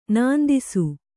♪ nāndisu